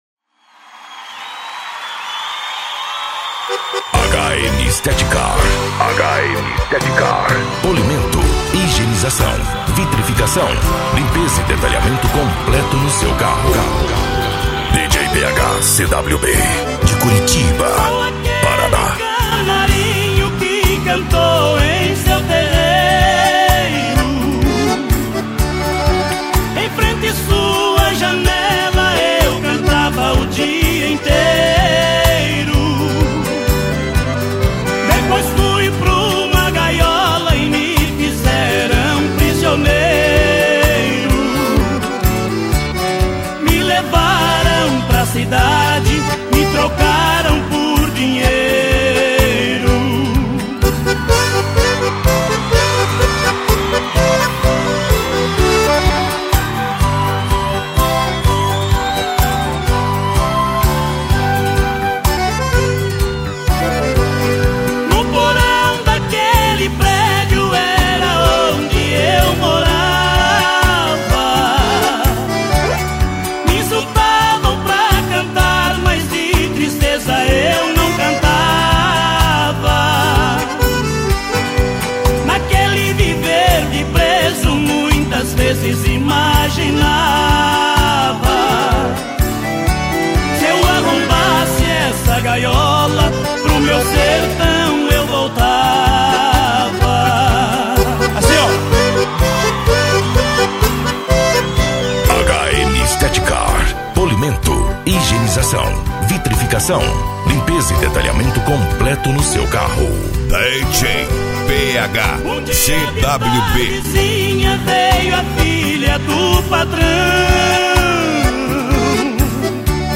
Eletronica
SERTANEJO